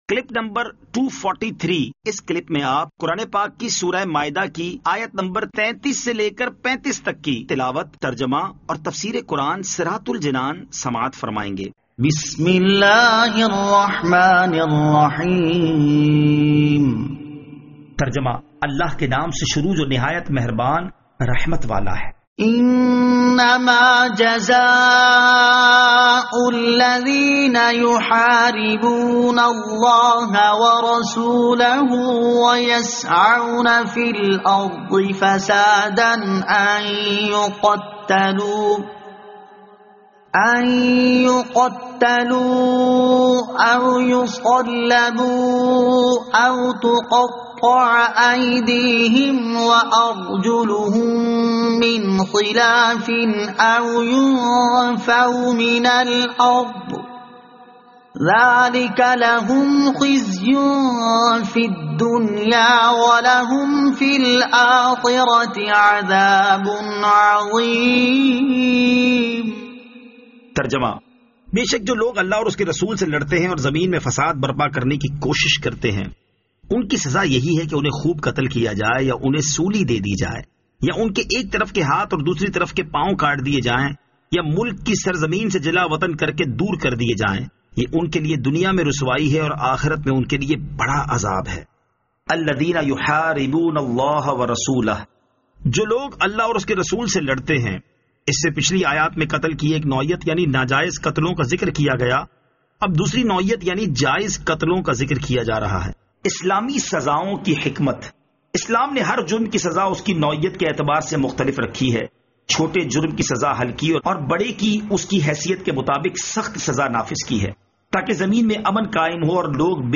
Surah Al-Maidah Ayat 33 To 35 Tilawat , Tarjama , Tafseer